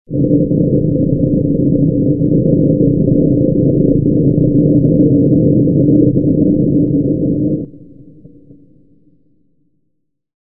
Звук полета ракеты внутри салона гул в космосе